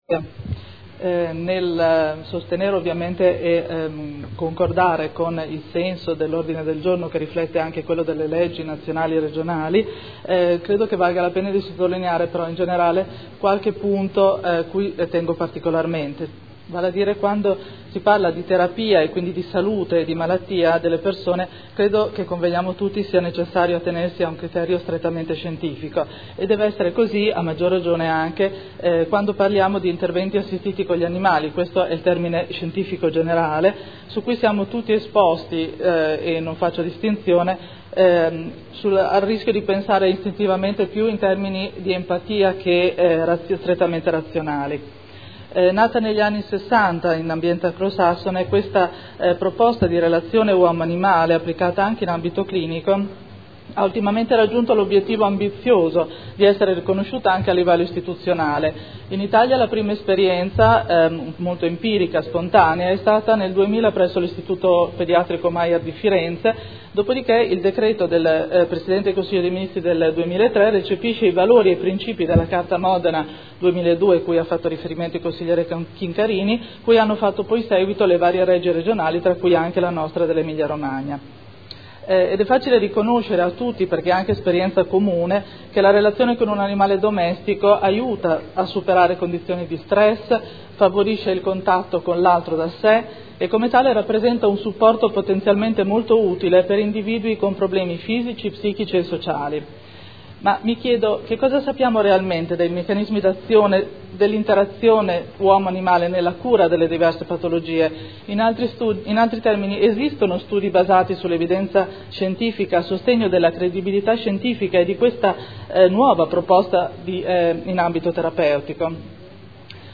Chiara Pacchioni — Sito Audio Consiglio Comunale